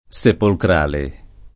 Sepolkr#le] (ant. sepulcrale [Sepulkr#le]) agg.